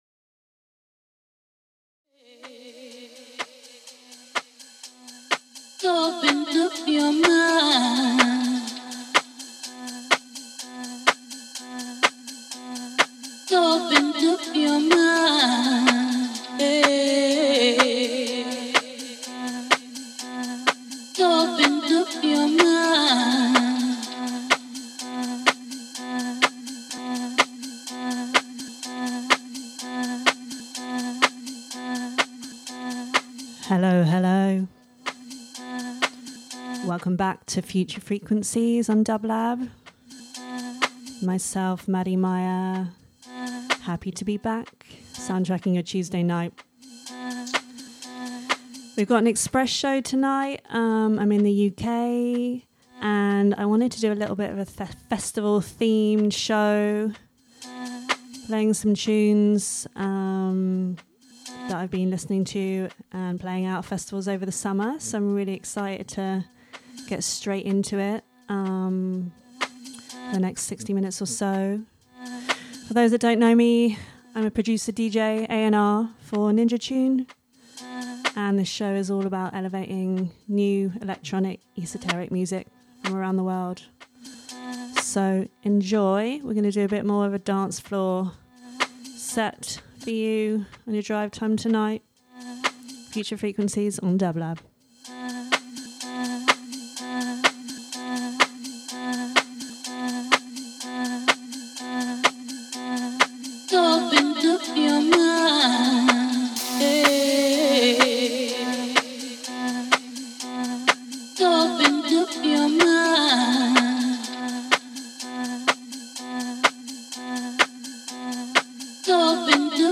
Dance Electronic House